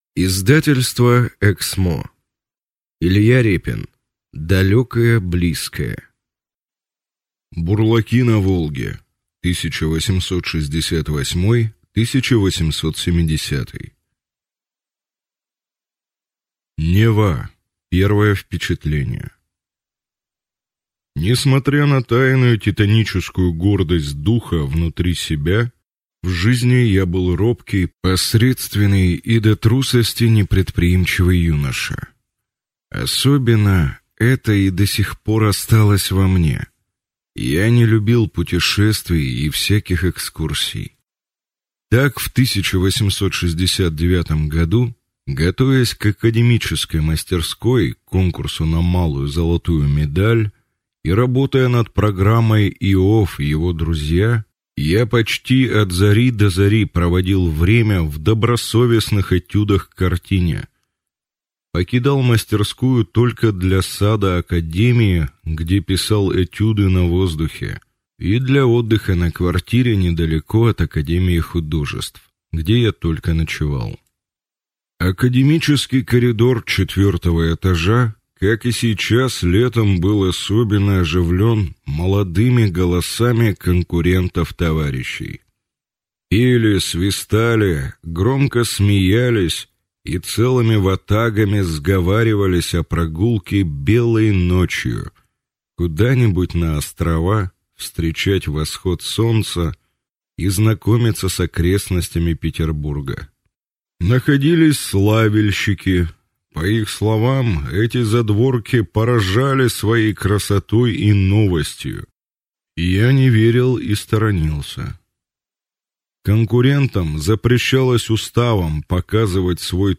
Аудиокнига Далекое близкое. Том 2 | Библиотека аудиокниг